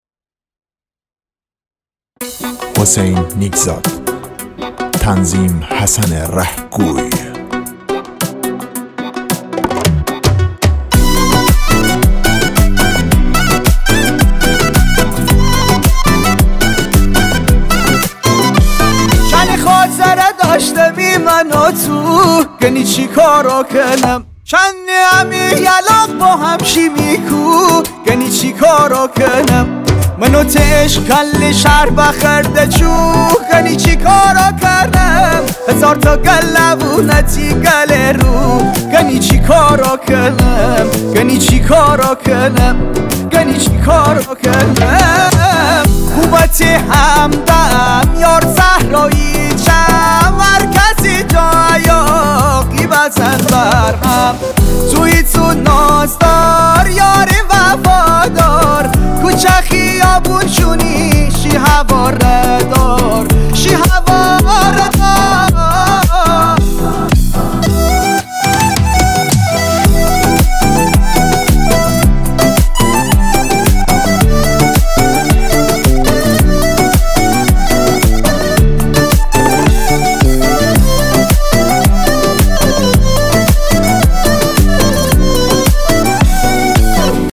ریتمیک ( تکدست )